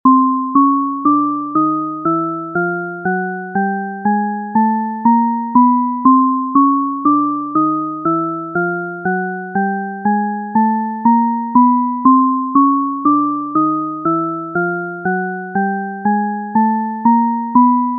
Эта хитрая слуховая иллюзия обманывает ваш мозг, заставляя его думать, что звук всегда повышается или понижается по высоте.
Звук простой шкалы Шепарда
Дискретная шкала Шепарда (тоны, начинающиеся с C1 и C3, переходят от тихого к громкому, а тоны, начинающиеся с C2 и C4, - от громкого к тихому)